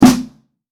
TC SNARE 10.wav